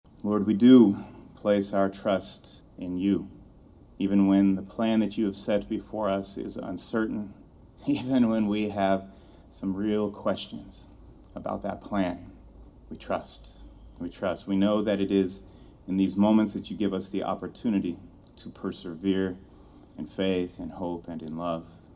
A community gathering was held outside Anderson Hall Tuesday evening as state, local and faith leaders came together in support of Kansas State University President Richard Linton, who earlier in the day announced his diagnosis of throat and tongue cancer.